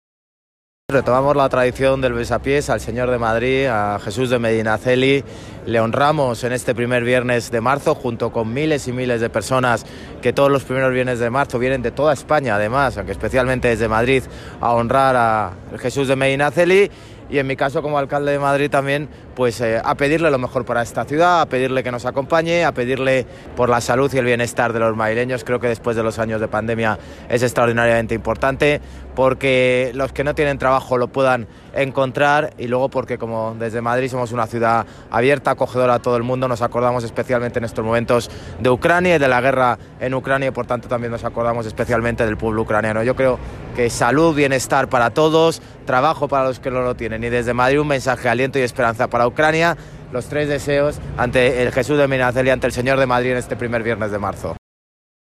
Nueva ventana:José Luis Martínez-Almeida, alcalde de Madrid
JLMartinezAlmeida-VisitaBasilicaJesusMedinaceli-03-03.mp3